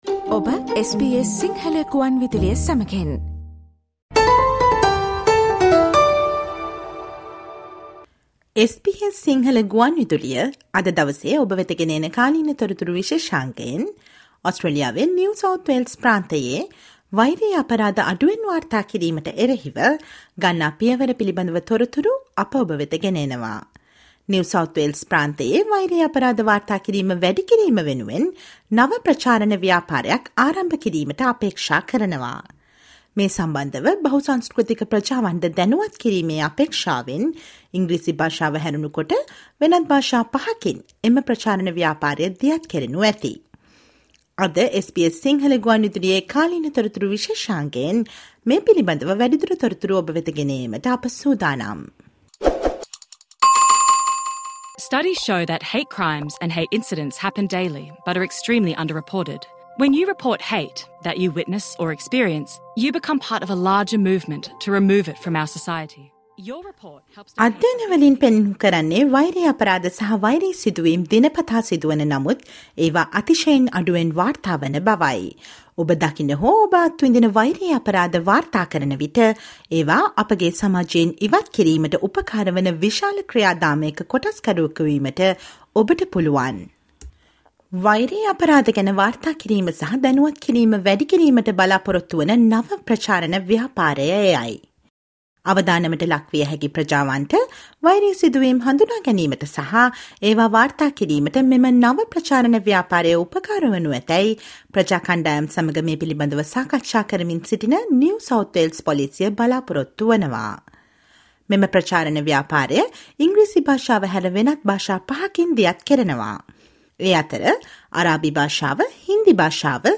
Listen to the SBS Sinhala radio current affair feature on the new campaign which is looking to increase the reporting of hate crimes in New South Wales and which is being launched in five languages other than English, hoping to raise awareness among multicultural communities.